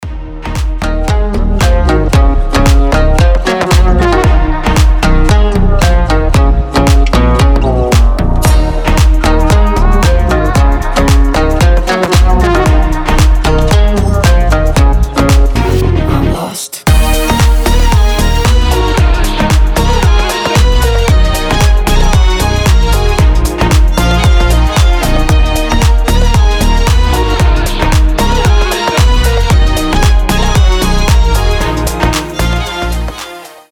• Качество: 320, Stereo
гитара
deep house
красивая мелодия
скрипка
восточные